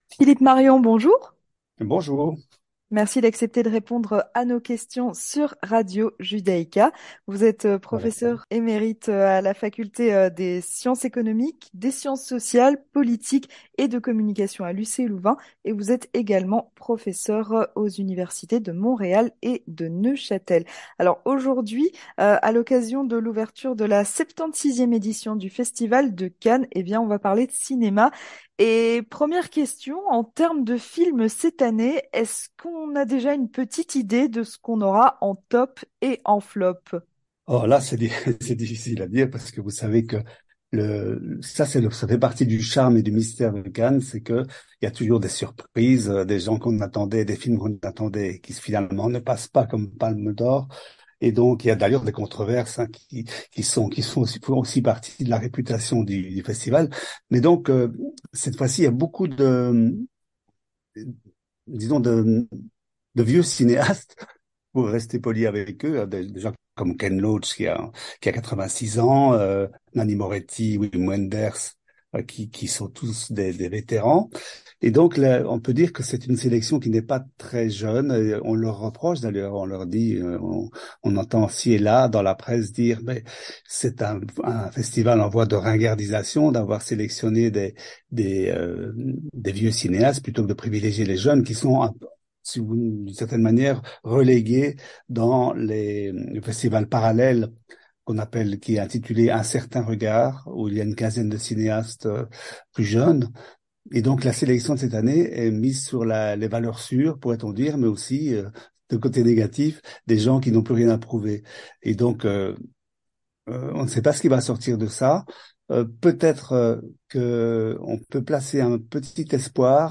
Entretien du 18h - Ouverture de la 76ème édition du Festival de Cannes